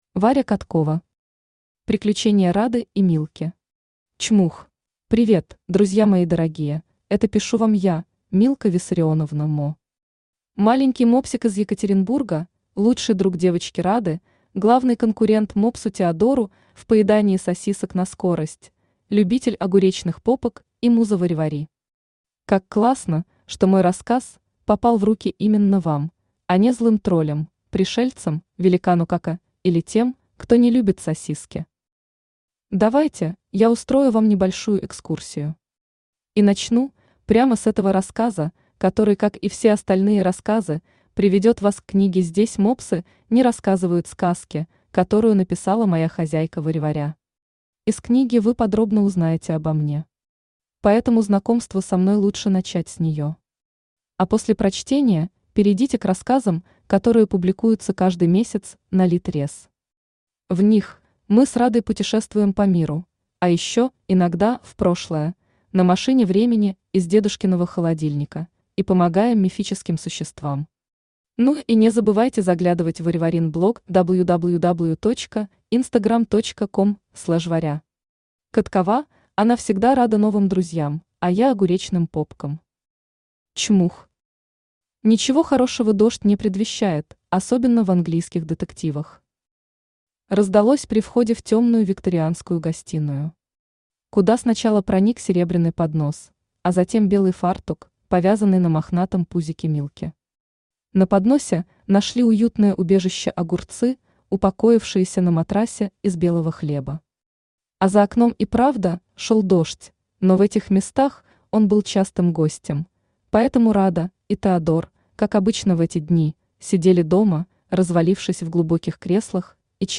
Аудиокнига Приключения Рады и Милки. Чмух | Библиотека аудиокниг
Чмух Автор Варя Каткова Читает аудиокнигу Авточтец ЛитРес.